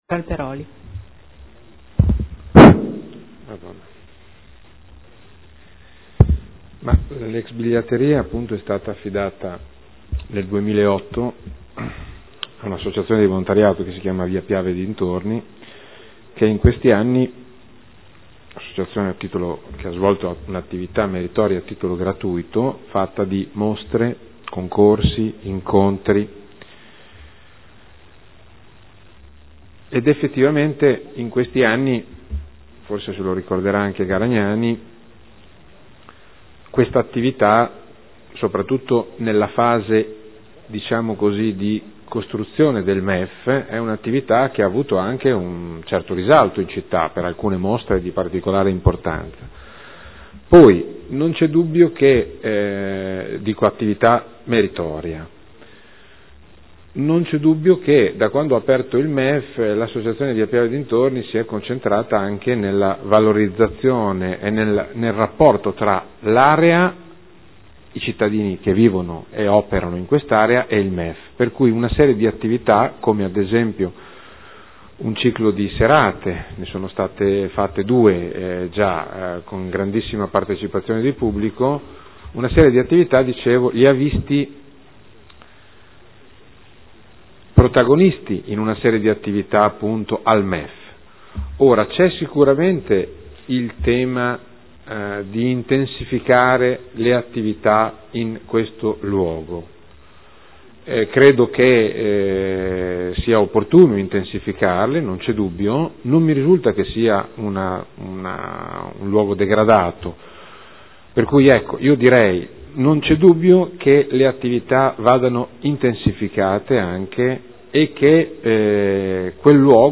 Roberto Alperoli — Sito Audio Consiglio Comunale